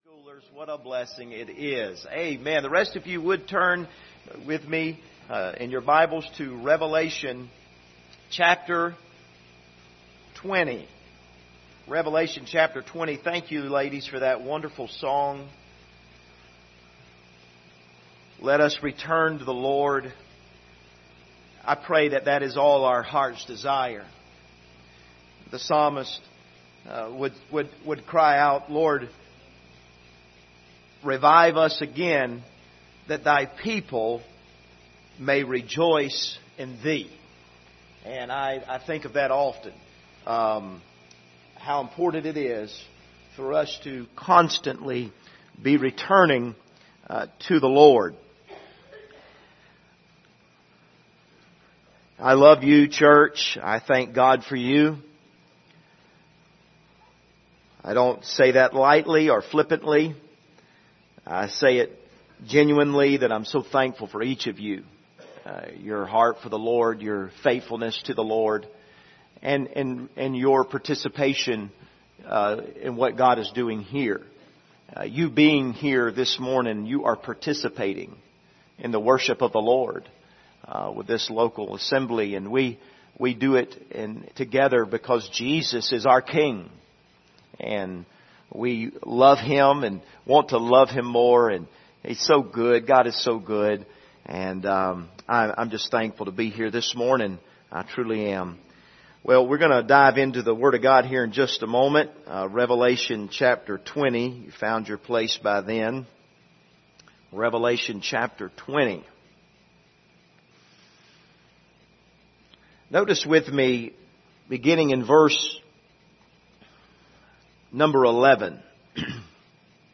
Passage: Revelation 20:10-21:8 Service Type: Sunday Morning